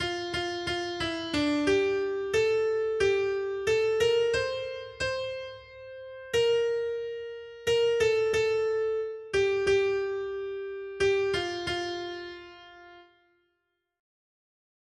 Noty Štítky, zpěvníky ol545.pdf responsoriální žalm Žaltář (Olejník) 545 Skrýt akordy R: Hospodin bude soudit svět podle práva. 1.